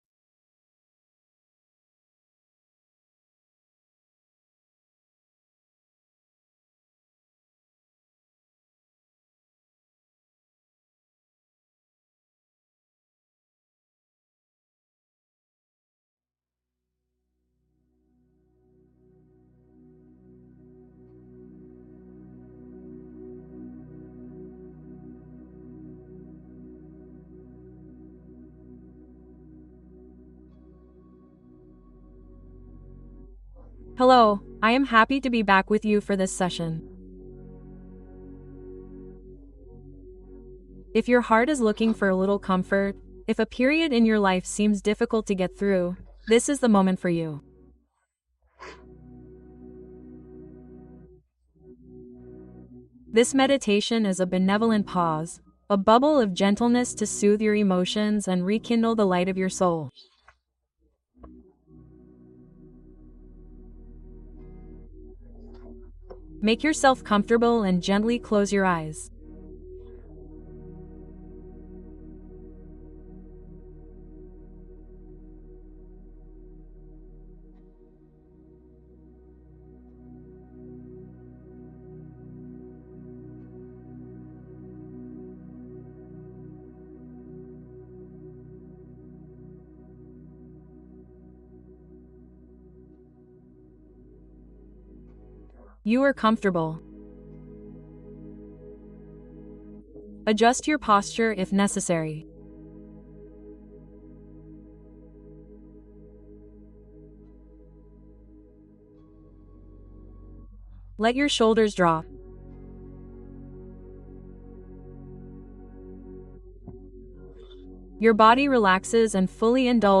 Le réconfort profond dont votre âme a désespérément besoin (méditation guidée émotionnelle)